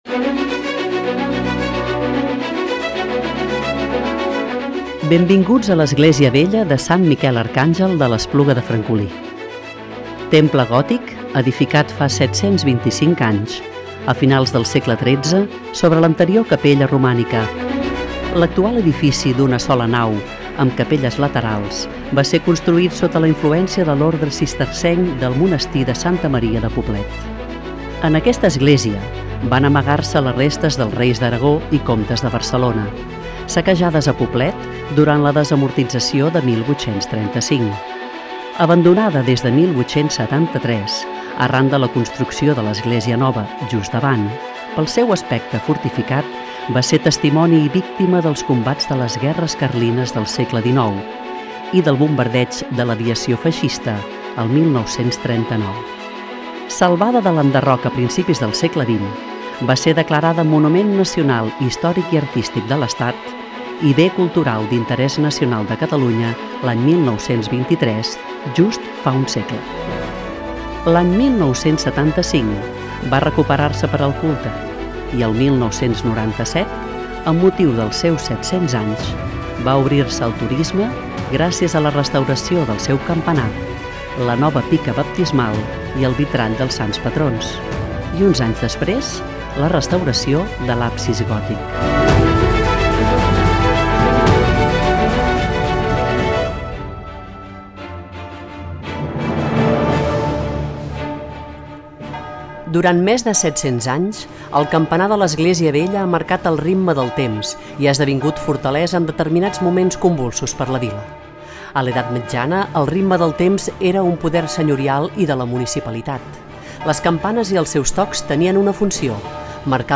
Audioguia-Esglesia-Vella-de-sant-Miquel-de-lEspluga-de-Francoli.mp3